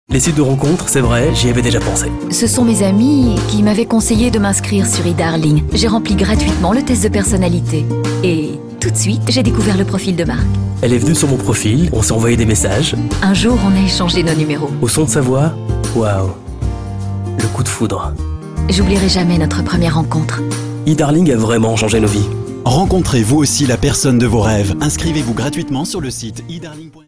FRENCH VO ENGLISH SPOKEN
Sprechprobe: Werbung (Muttersprache):